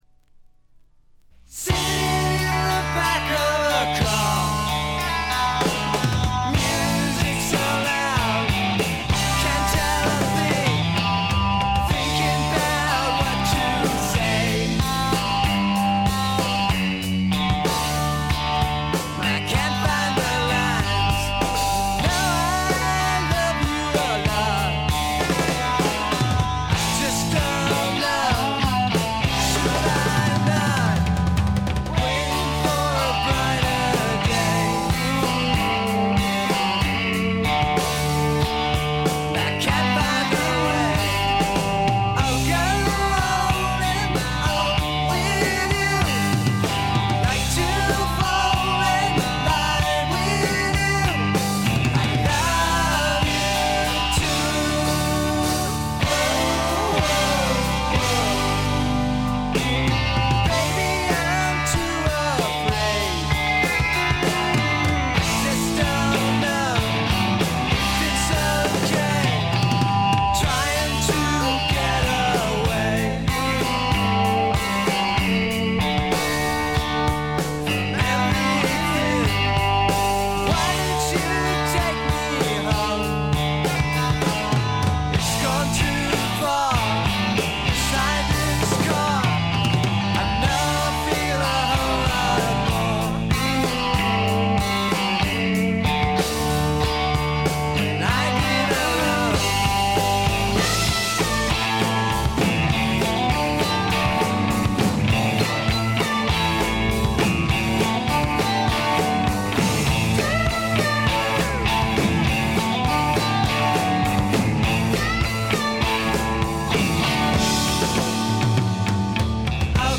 散発的なプツ音が3ヶ所ほど（ほとんど気付かないレベル）。
試聴曲は現品からの取り込み音源です。